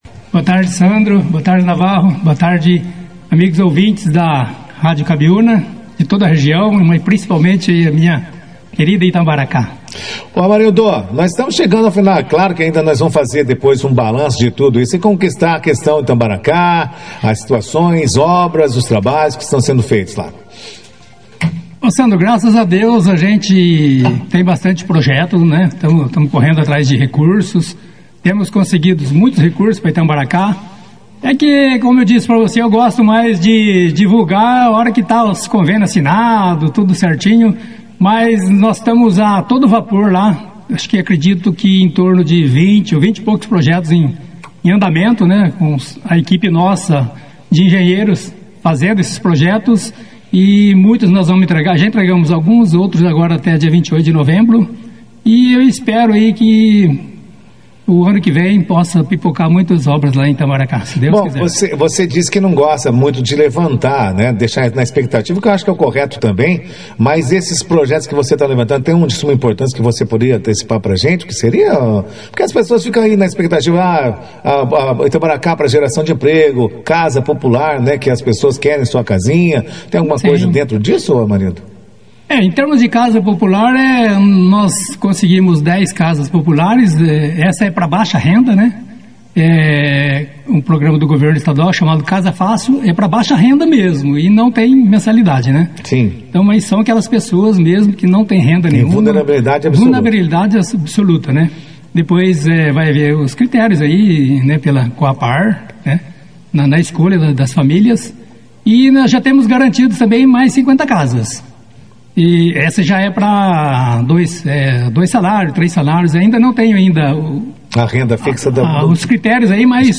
O prefeito de Itambaracá, Amarildo Tostes, participou nesta quarta-feira, dia 19, do Jornal Operação Cidade, onde comentou uma série de assuntos ligados à administração municipal.